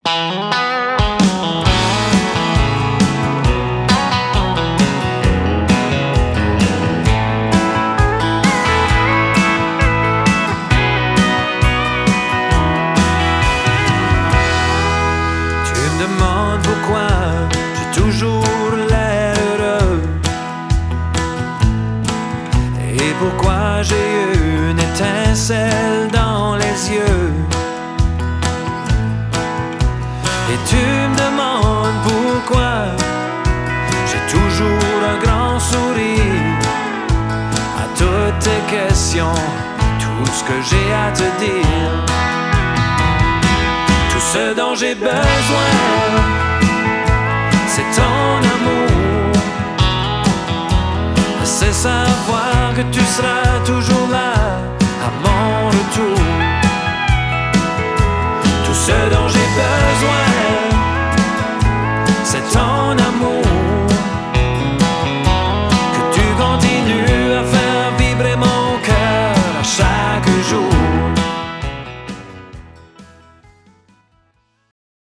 style country traditionnel acadien